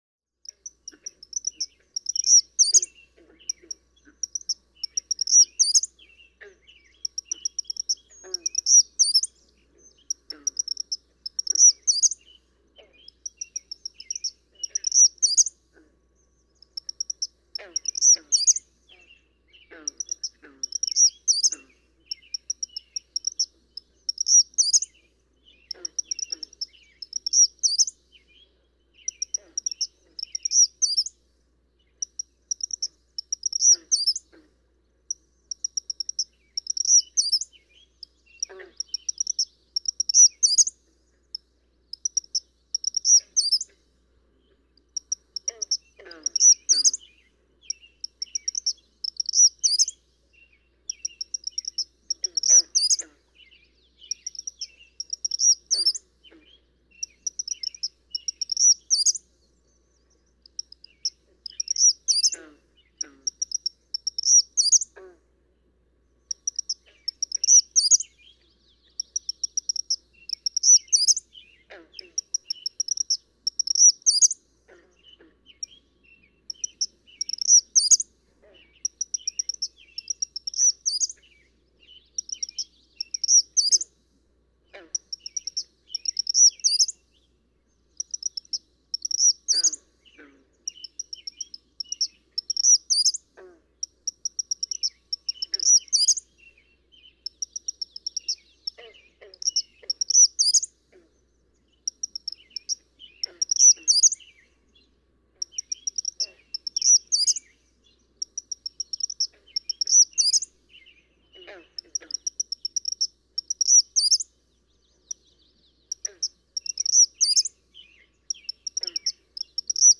♫612. Eastern kingbird: Eastern kingbirds at dawn sputter and stutter sharply, t't'tzeer, t't'tzeer, t't'tzeer, t't'tzeer, t't'tzeer, eventually exploding into a t'tzeetzeetzee.
612_Eastern_Kingbird.mp3